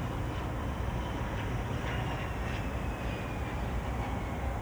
jetDive.wav